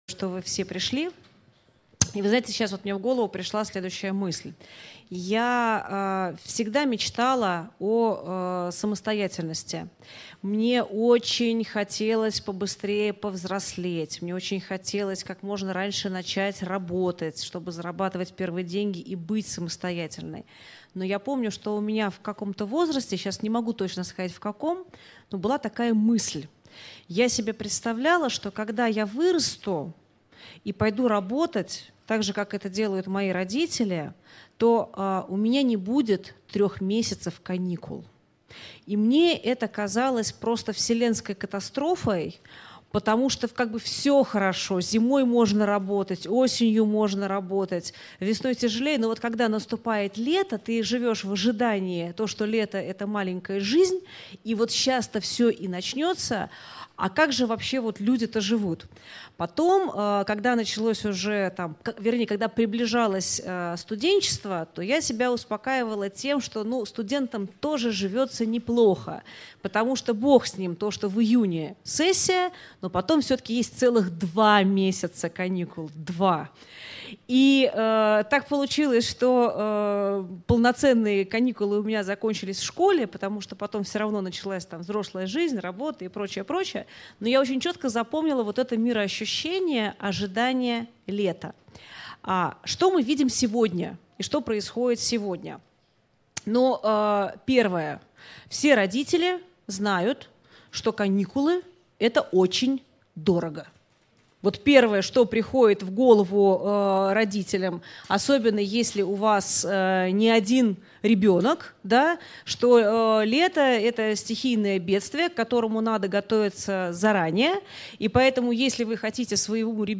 Аудиокнига Зачем ребенку каникулы?